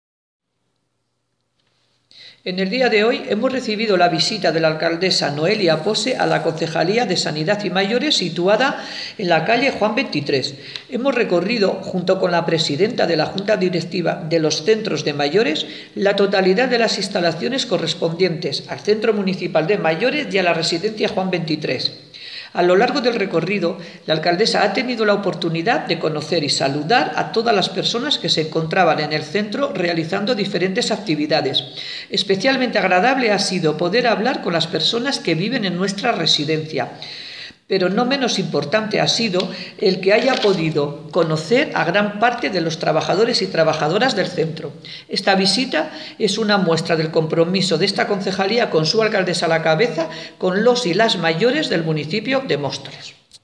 Audio - Ana María Rodrigo (Concejala Concejalía de Igualdad, Sanidad y Mayores) Sobre Junta de Mayores